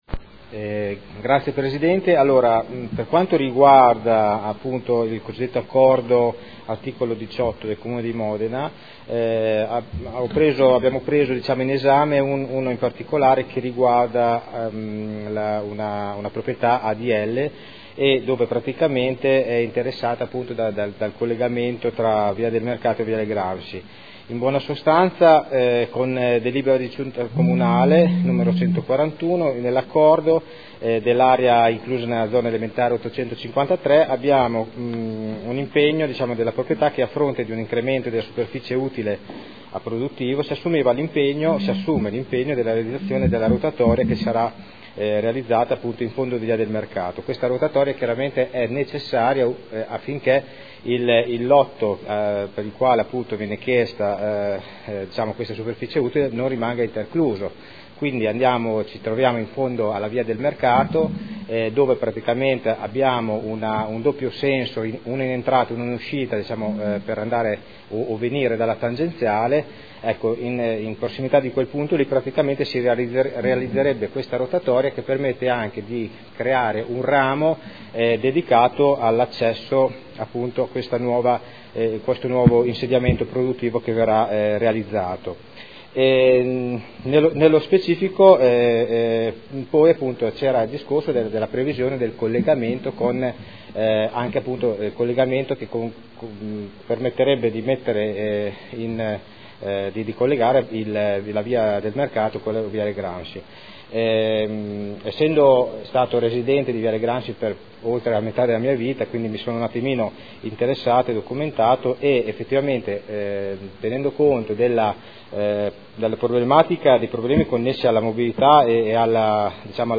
Vincenzo Walter Stella — Sito Audio Consiglio Comunale